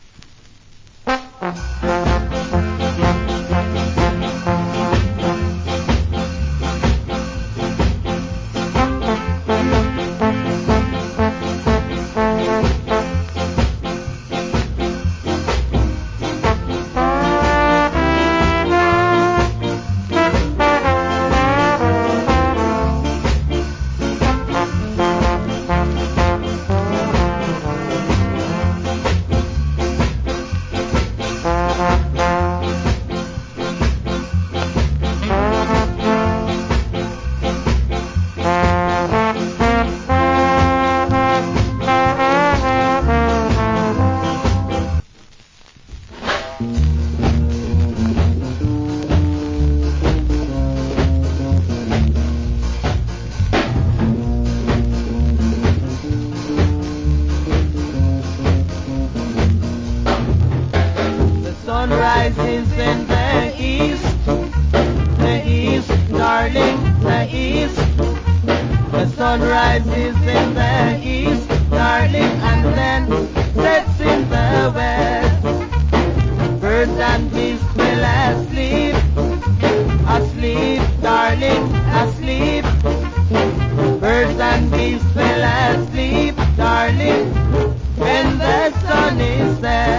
Authentic Ska Inst.